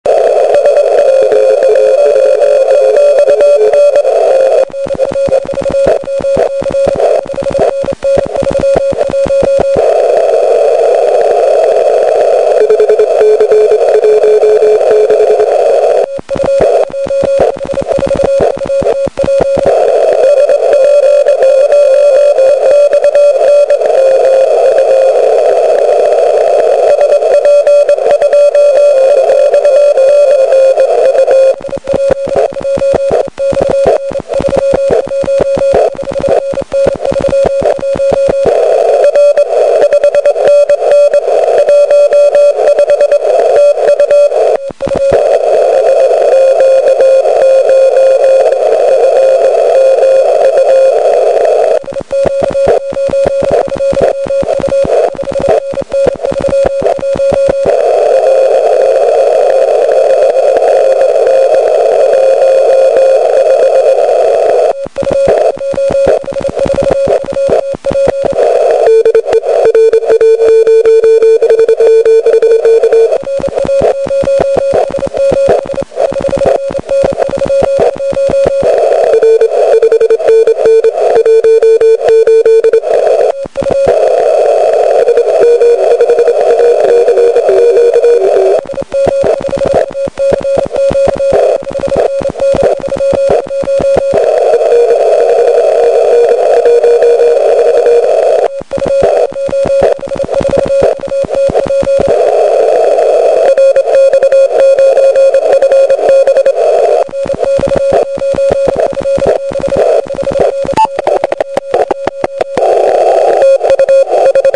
今回も全て録音しました。SO2R box で分岐してステレオ録音する筈が、帰って聞きなおすとレコーダの設定がモノのままになっていました。